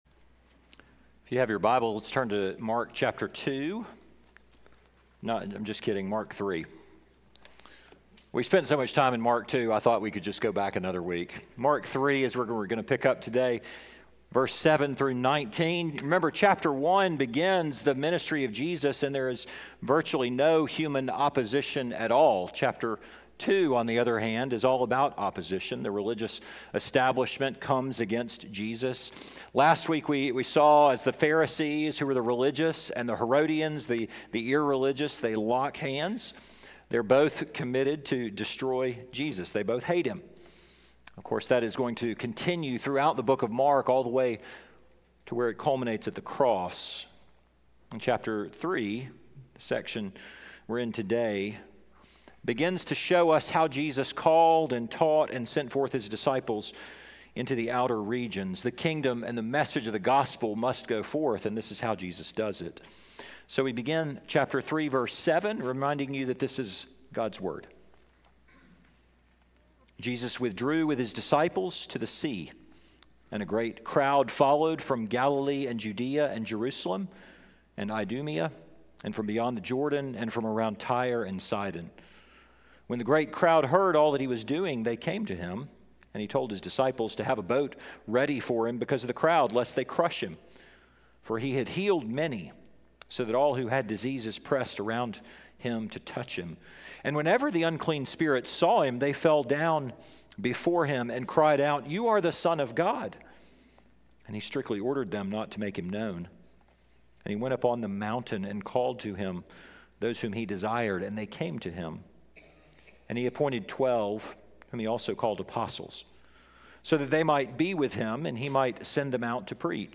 2023 Built on the Ordinary Preacher